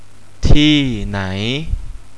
Where - "Thee Nai"